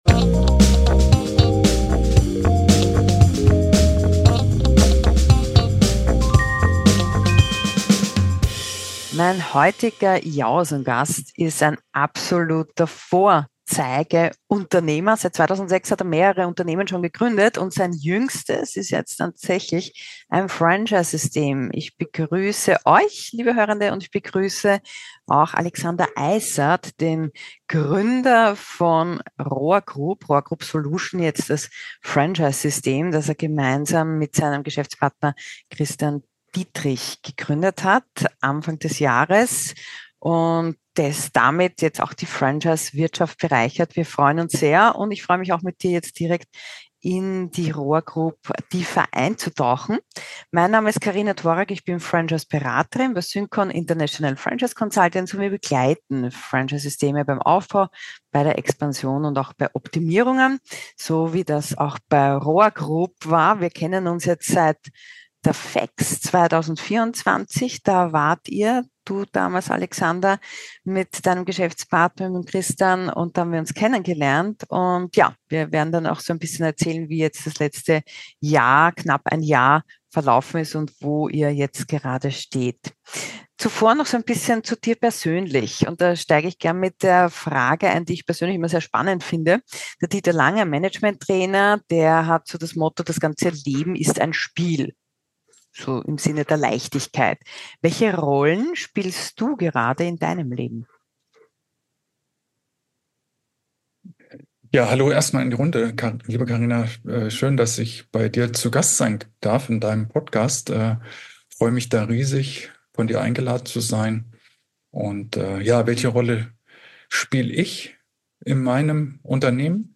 Ein Gespräch über Rohrsanierung, 3D-Druck, Pipe prints, Quereinsteiger und Franchiseseminare